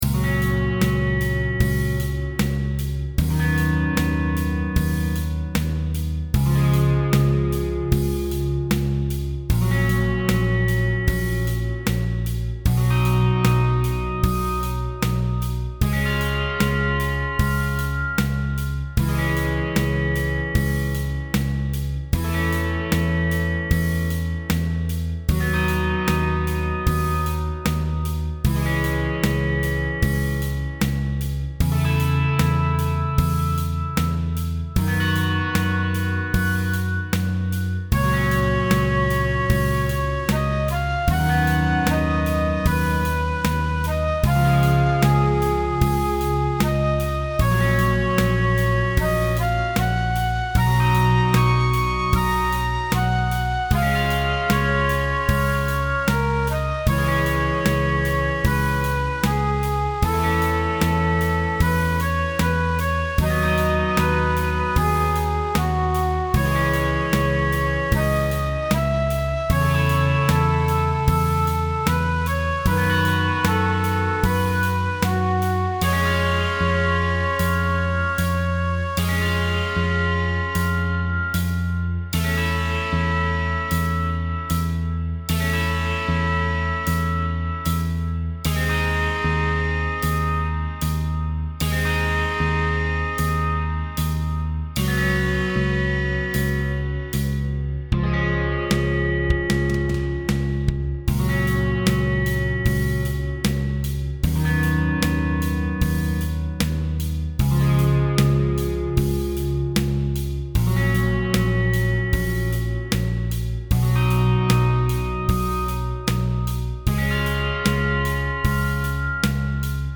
game music